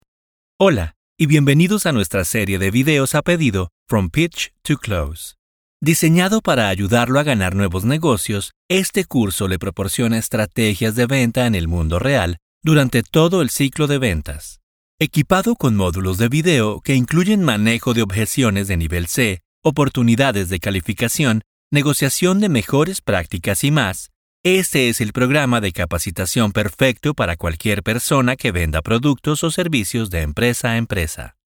Smooth, Energetic, Professional
eLearning